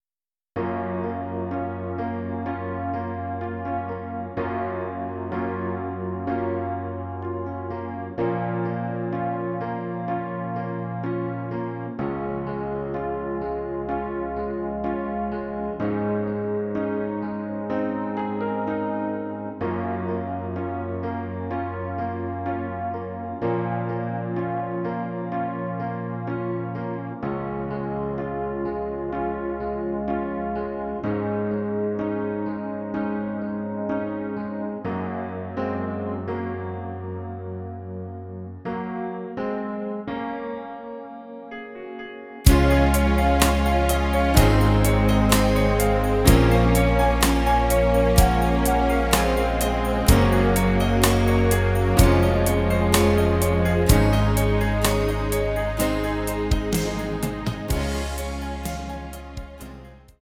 Rhythmus  Slow
Art  Deutsch, Schlager 2010er